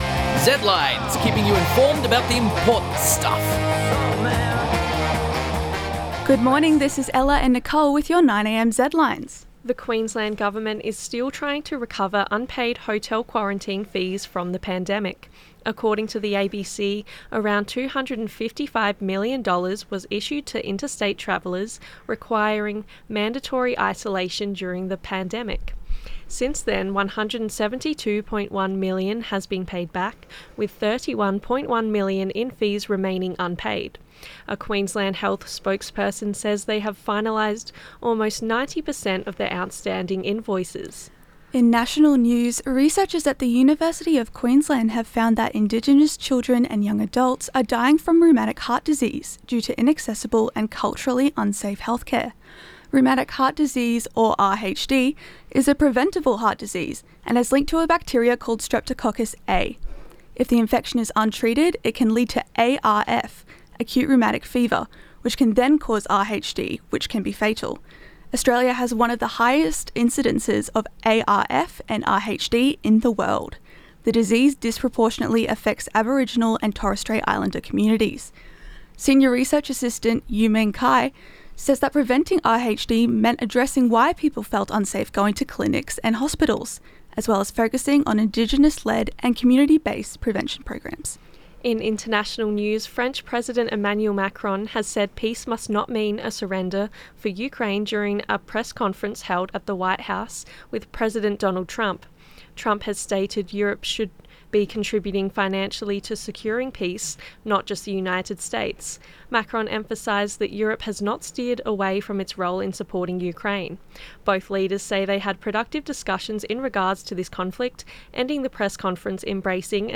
Emmanuel Macron (Jacques Paquier/flickr under CC BY-ND 2.0) Zedlines Bulletin 9am ZEDLINES 250225.mp3 (5.59 MB)